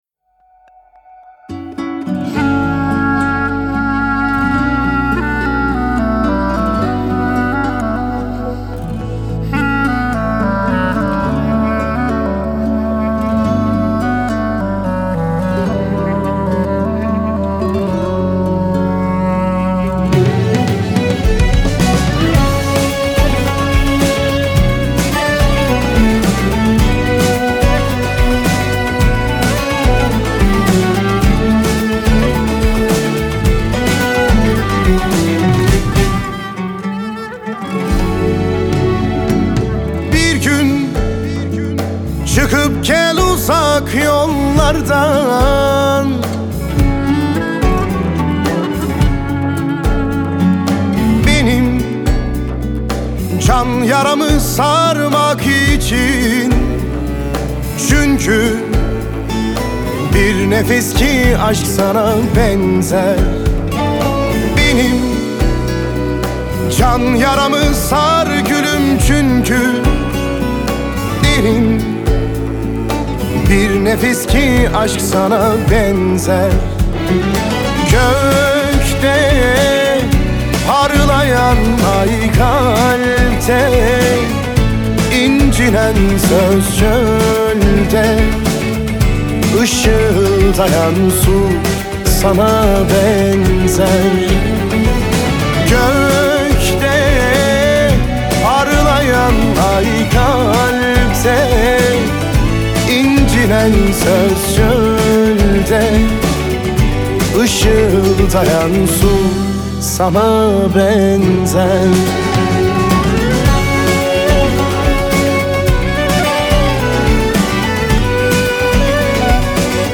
دانلود آهنگ اشک آور ترکی استانبولی – ۱۶